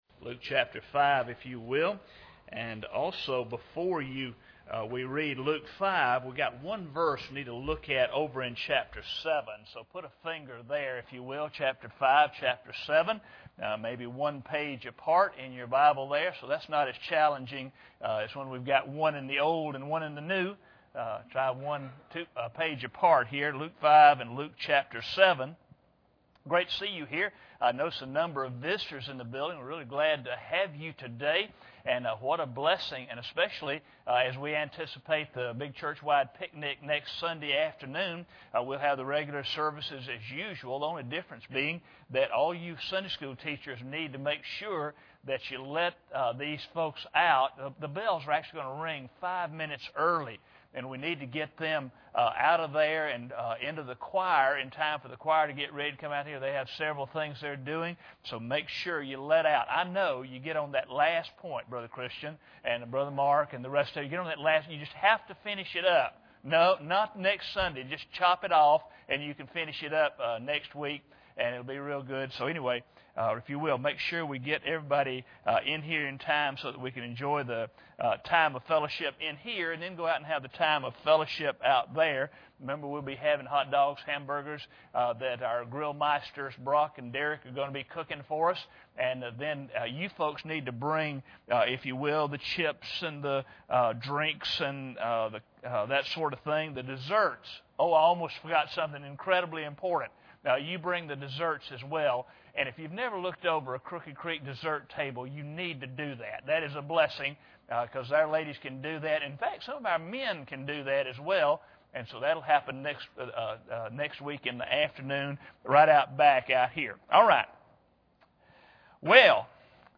Bible Text: Luke 7:34 | Preacher